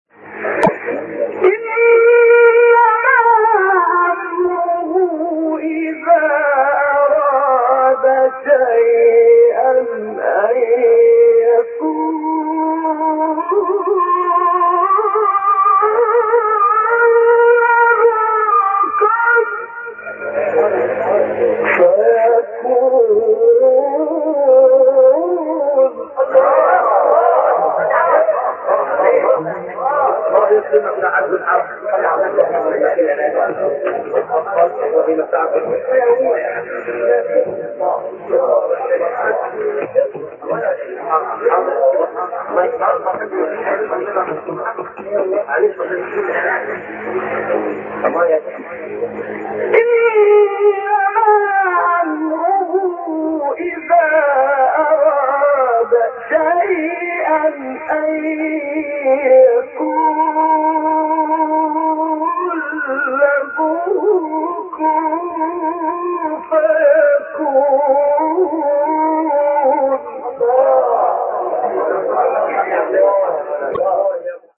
تلاوت آیه 82 سوره یس استاد حصان | نغمات قرآن
سوره : یس آیه : 82 استاد : محمد عبدالعزیز حصان مقام : رست قبلی بعدی